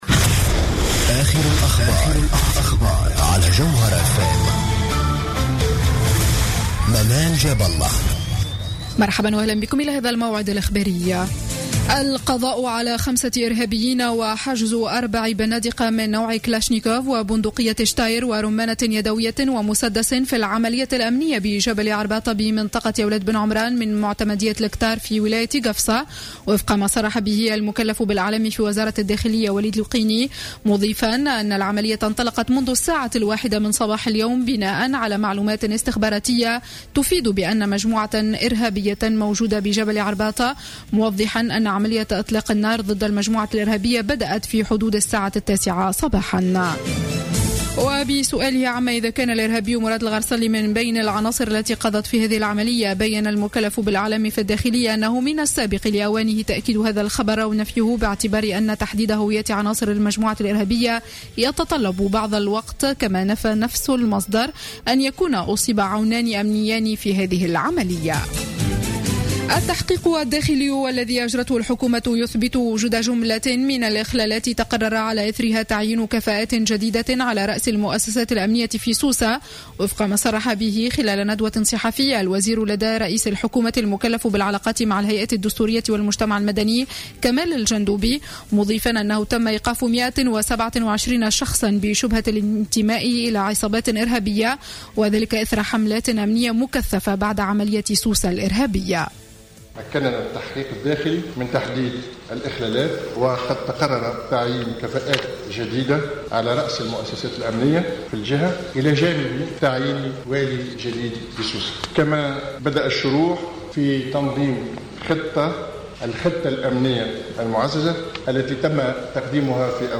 نشرة أخبار الساعة الخامسة مساء ليوم الجمعة 10 جويلية 2015